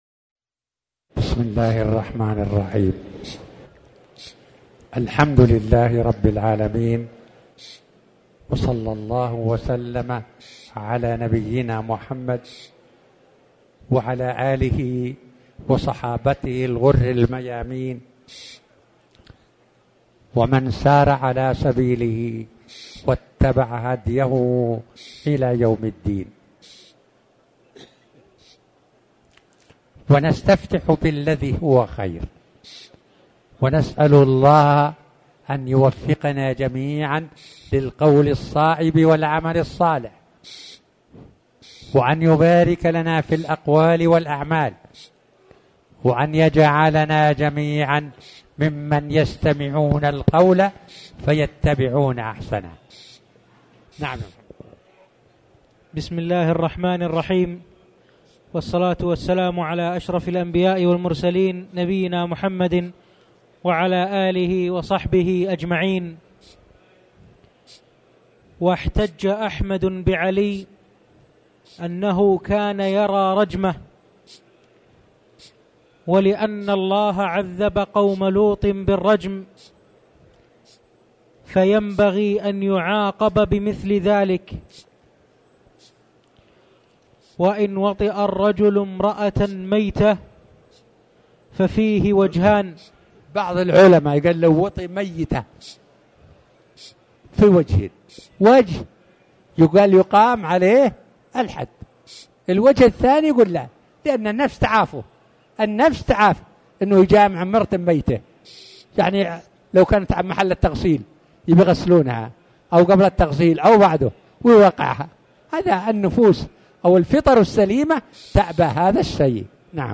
تاريخ النشر ١٣ ربيع الأول ١٤٤٠ هـ المكان: المسجد الحرام الشيخ